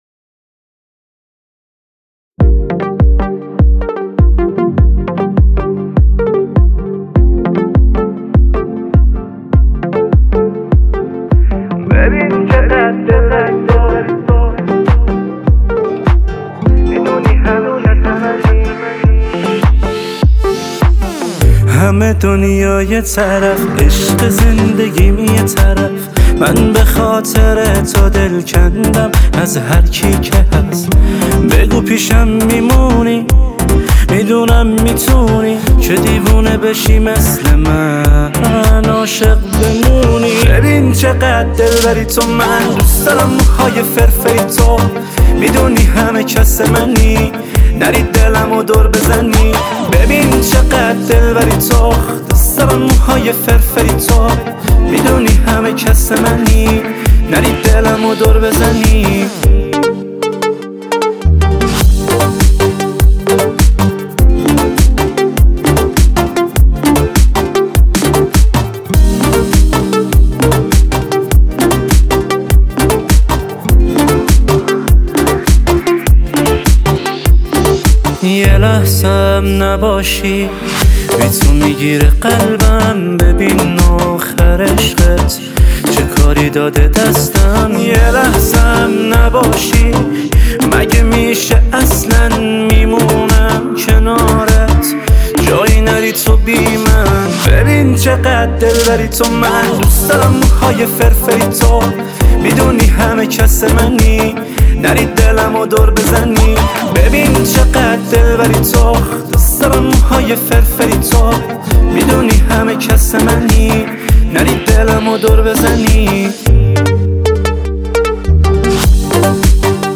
Listen And Download Pop Music